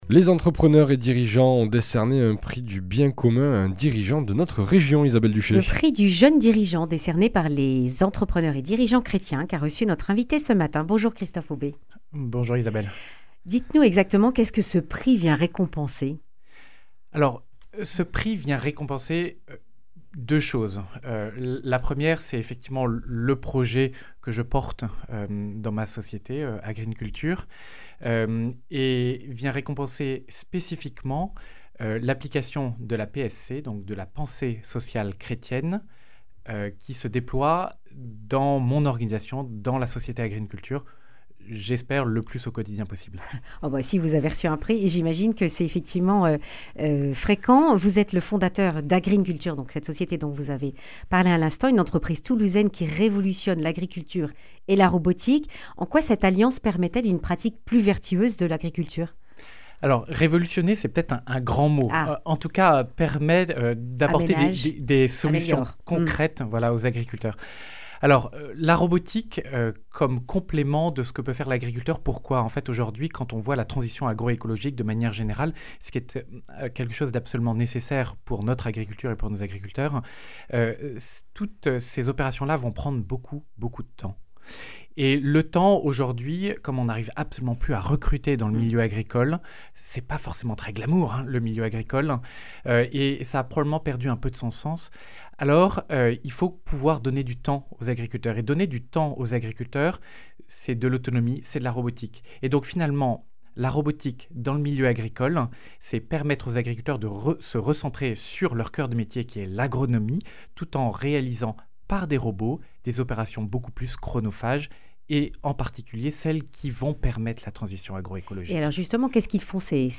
lundi 16 juin 2025 Le grand entretien Durée 10 min